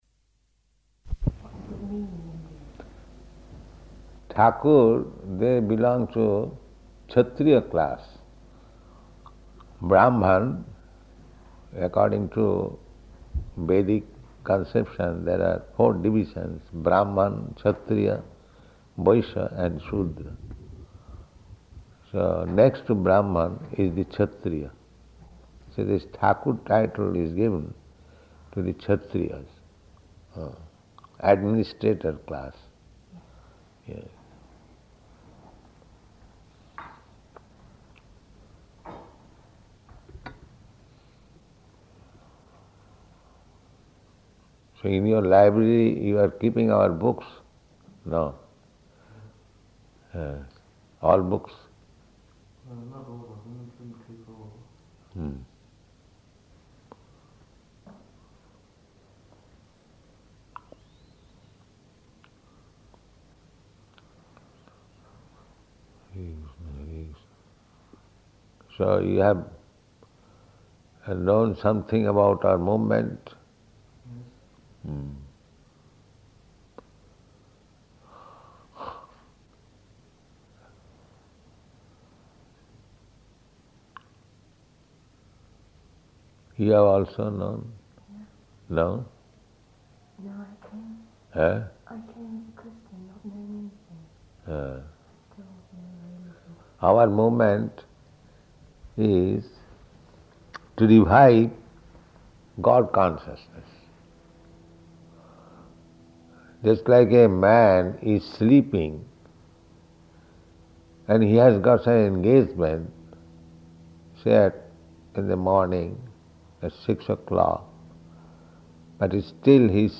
Room Conversation with Educationists
Room Conversation with Educationists --:-- --:-- Type: Conversation Dated: July 13th 1973 Location: London Audio file: 730713R1.LON.mp3 Guest (1) [young British woman]: What was the meaning in that?